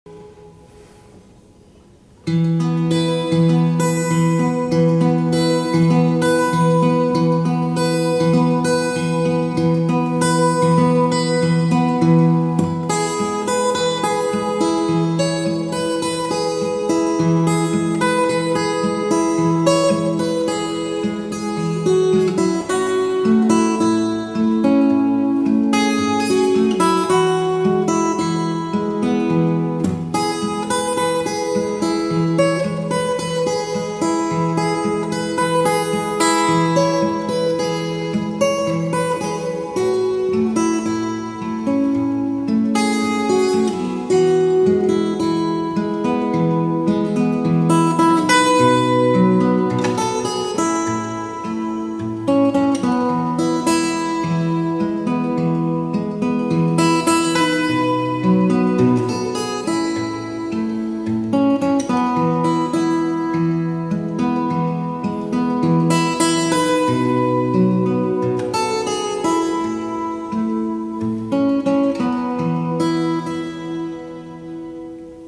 Audio Clip from the Tutorial
Capo 4th Fret - 4/4 Time